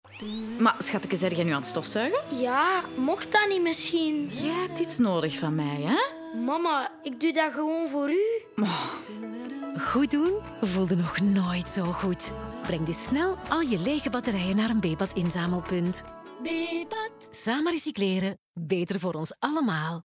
Sound Production & Sound Design: La Vita Studios
250324-Bebat-radio-mix-OLA--23LUFS-stofzuiger-NL-20.mp3